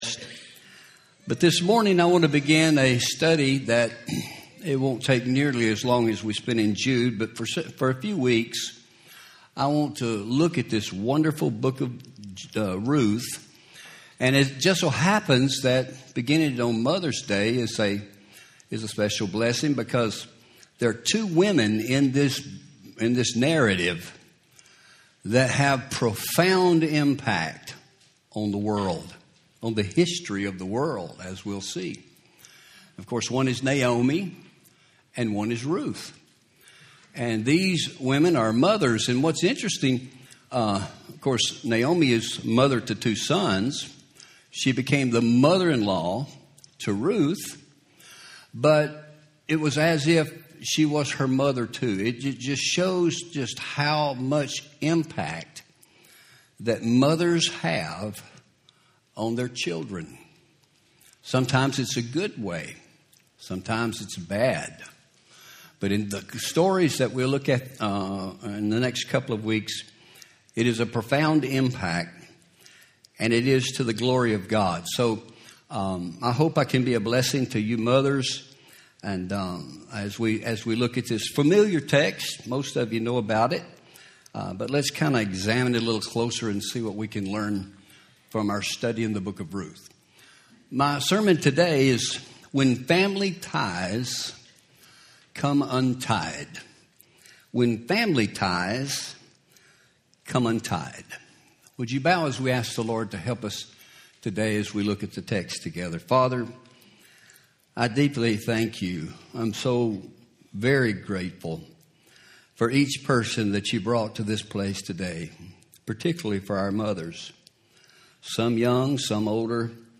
Home › Sermons › When Family Ties Come Untied